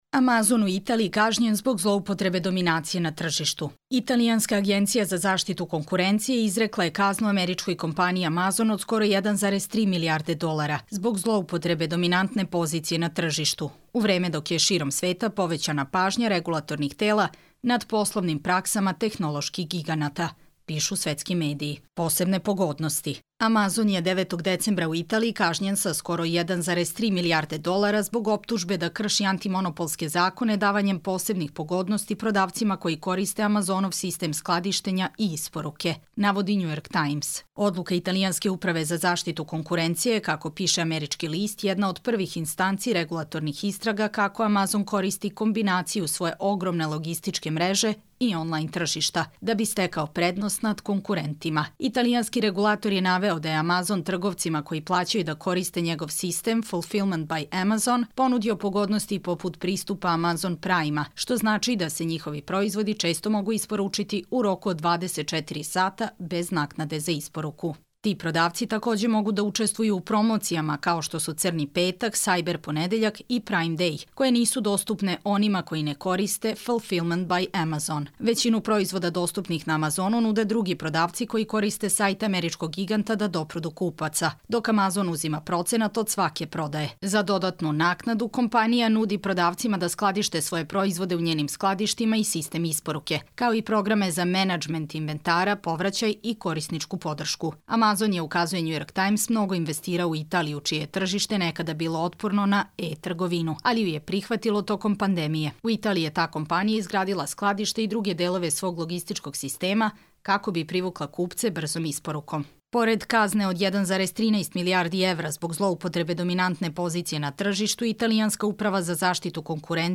Čitamo vam: Amazon u Italiji kažnjen zbog zloupotrebe dominacije na tržištu